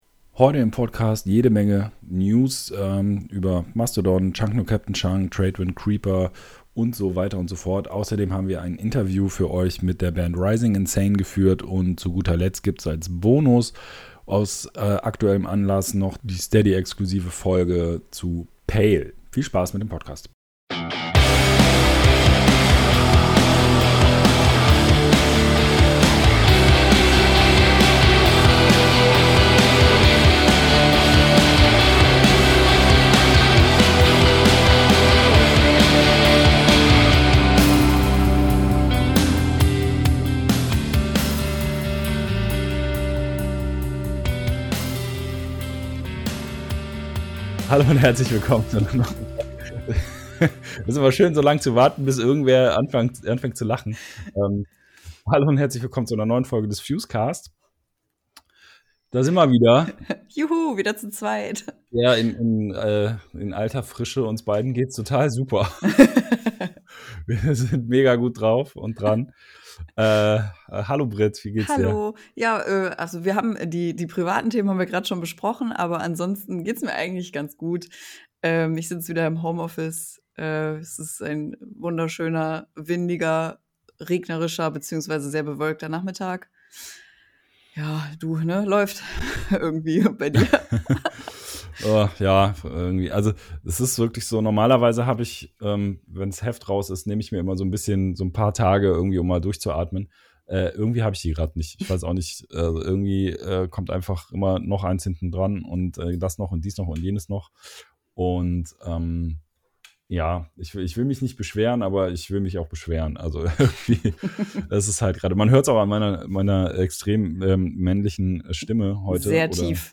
Mai 2021 Nächste Episode download Beschreibung Teilen Abonnieren Heute im Podcast: Jede Menge News zu PALE, MASTODON, ARCHITECTS, CHUNK! NO, CAPTAIN CHUNK!, TRADEWIND, CREEPER, BLACKOUT PROBLEMS, NATHAN GRAY und SLIPKNOT. Außerdem im Interview